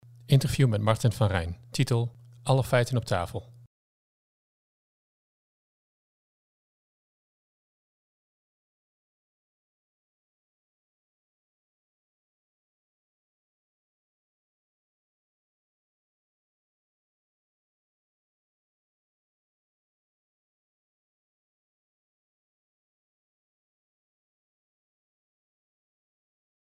Interview met Martin van Rijn.